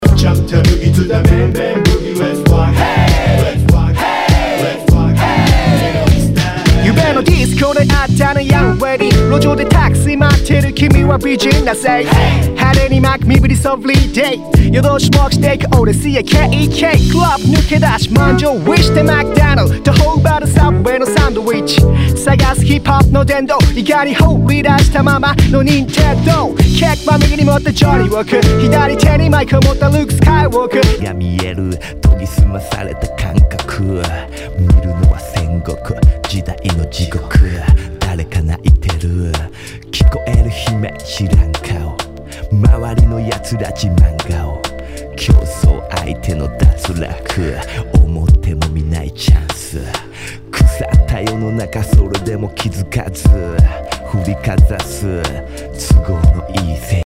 HIPHOP/R&B
ナイス！Jヒップホップ！